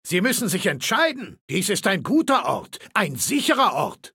Datei:Maleold01 ms06 ms06hamlininfo 0005a20b.ogg
Fallout 3: Audiodialoge